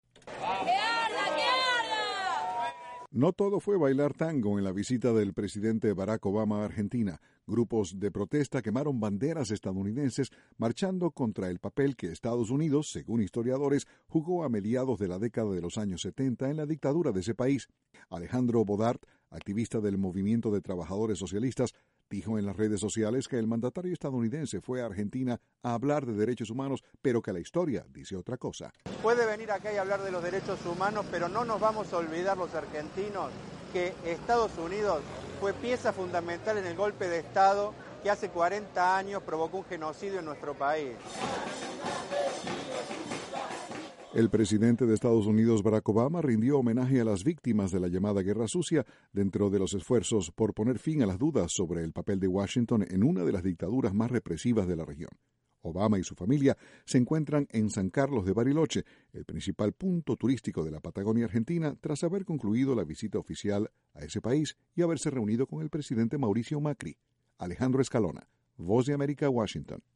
Fuertes protestas en Argentina por la visita del presidente Barack Obama. Desde la Voz de América, Washington